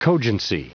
Prononciation du mot cogency en anglais (fichier audio)
Prononciation du mot : cogency